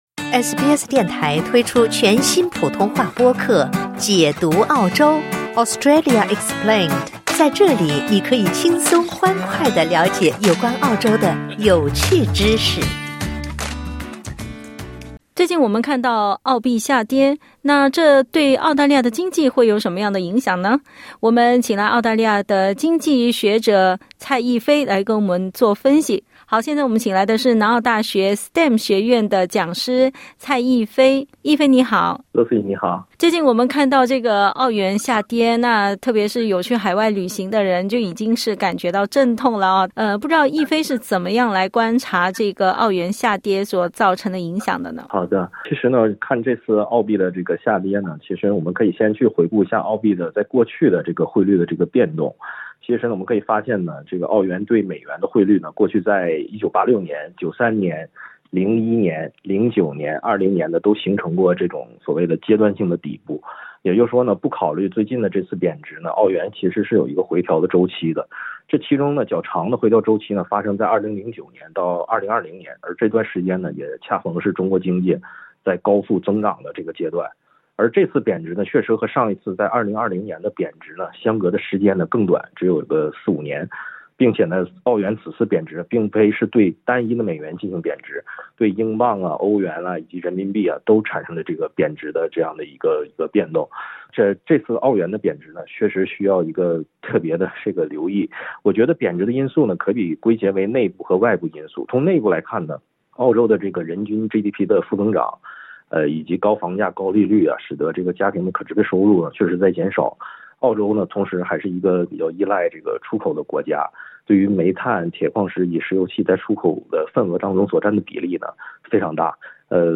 （点击音频收听详细采访） 近日，澳元兑美元汇率接近新冠疫情初期以来的最低水平，澳元兑美元跌至57.5美分的低点。